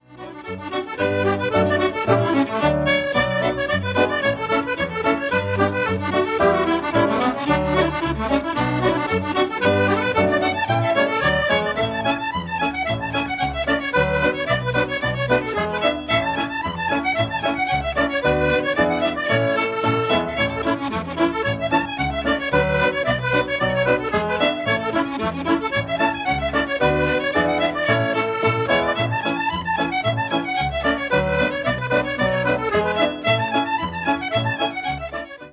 accordion
fiddle
piano
bass